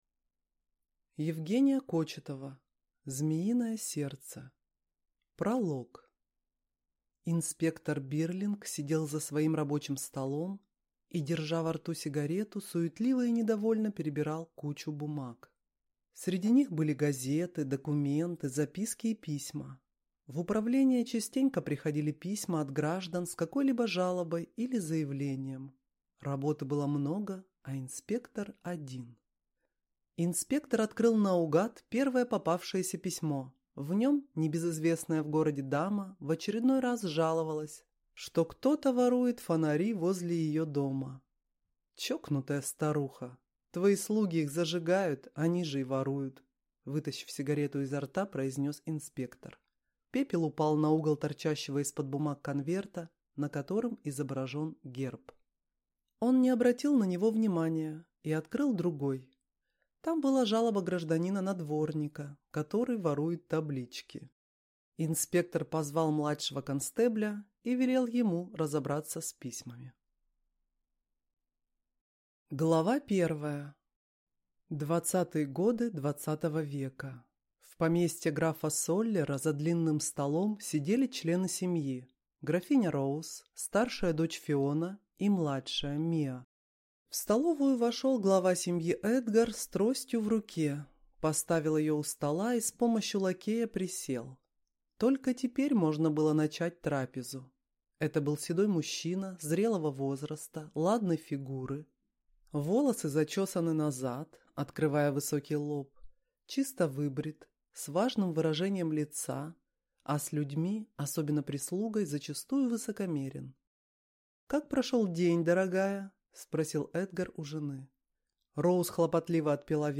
Аудиокнига Змеиное сердце | Библиотека аудиокниг